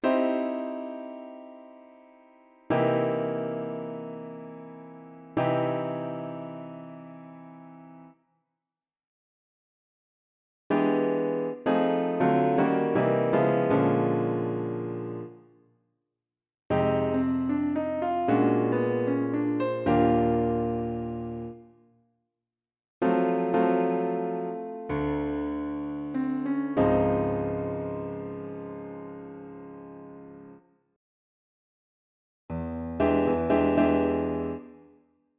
Sharp 9 Chord Extensions | Jazz For Piano